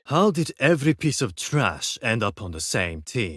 EN YORU From Valorant Random Voice Lines